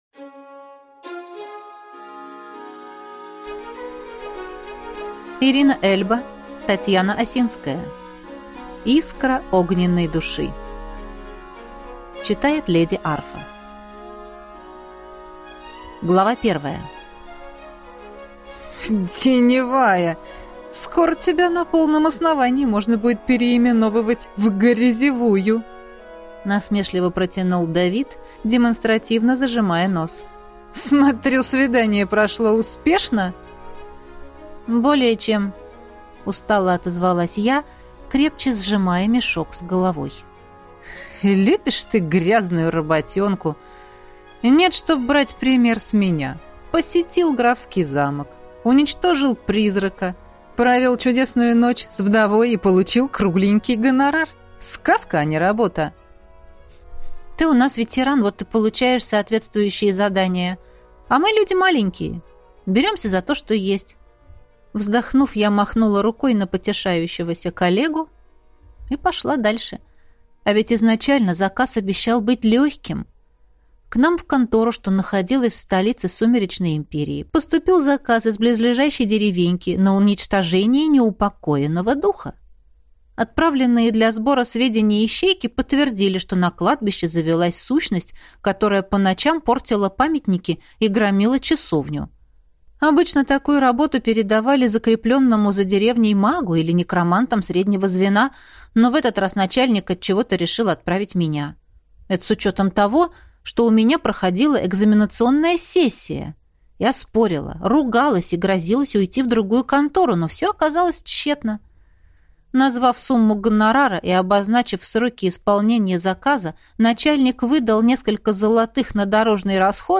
Аудиокнига Искра огненной души | Библиотека аудиокниг